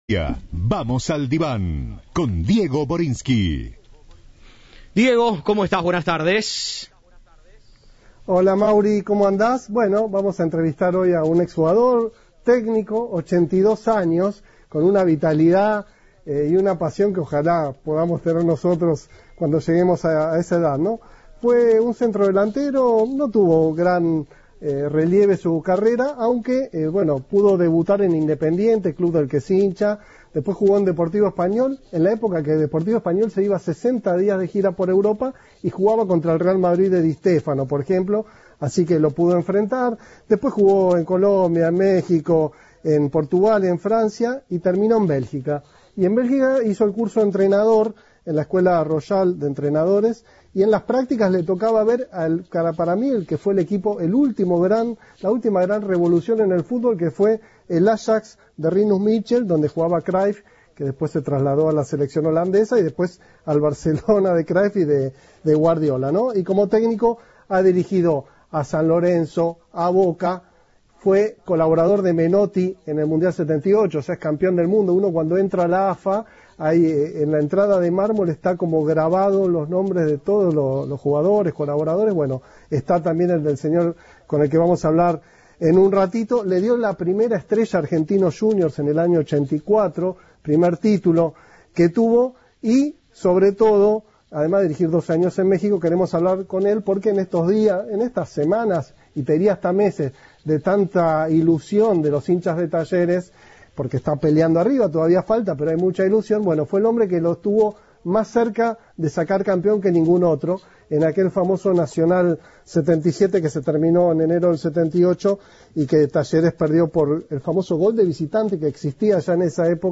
El técnico que estuvo más cerca de sacar campeón al conjunto cordobés y que descubrió a Andrés Fassi en su juventud dialogó Cadena 3 y recordó su paso por el club y el día en el que Maradona quedó afuera de la Copa del Mundo de 1978.